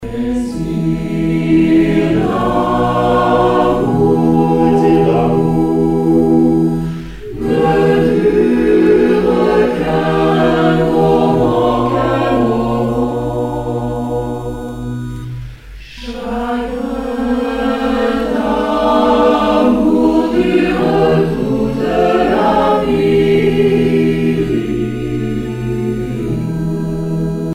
Genre strophique Artiste de l'album Profs du collège Saint-Joseph de Longué